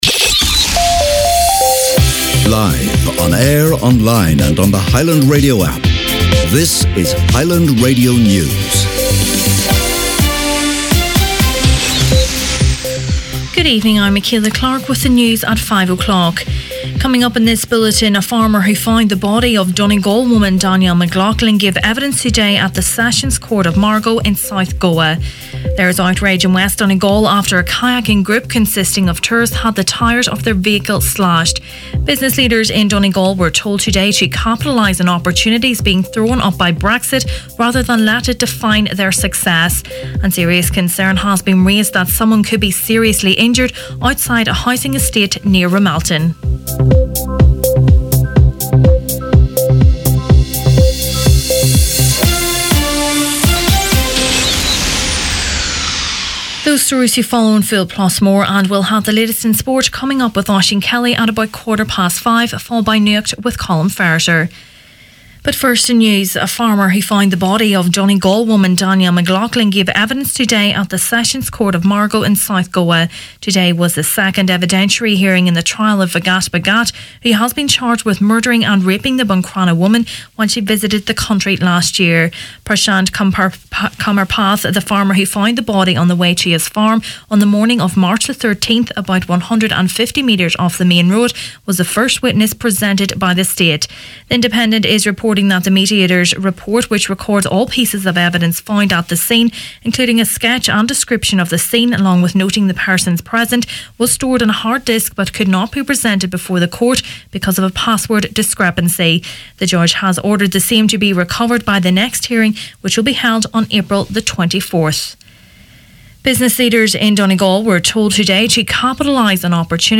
Main Evening News, Sport, Nuacht and Obituaries Friday 13th April